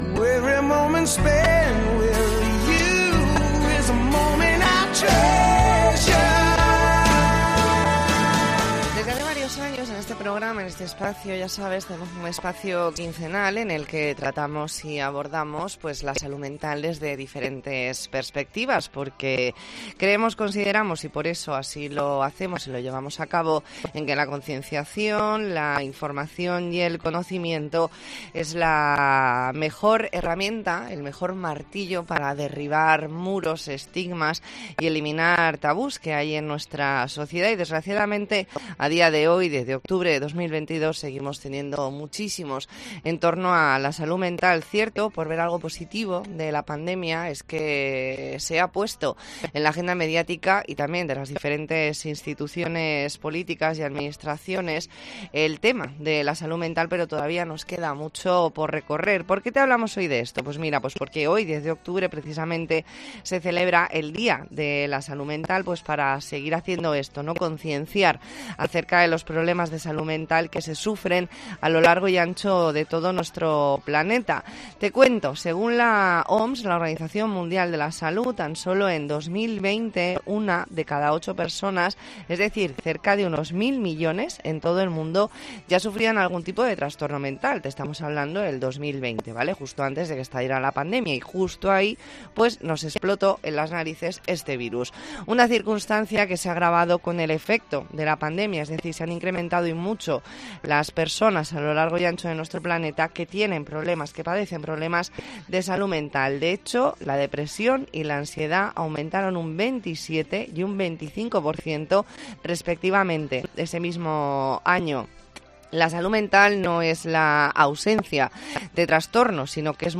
E ntrevista en La Mañana en COPE Más Mallorca, lunes 10 de octubre de 2022.